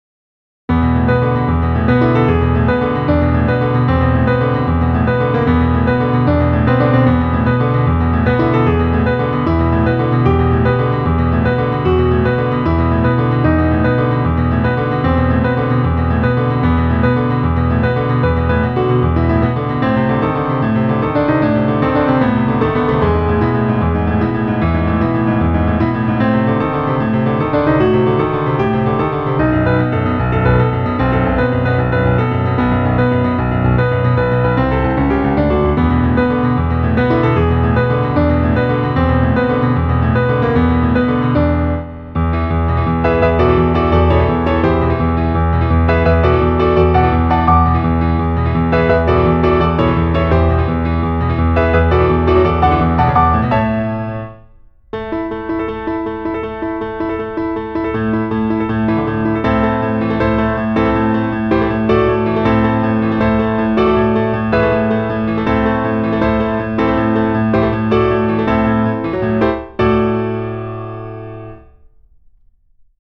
Ivory 3 German D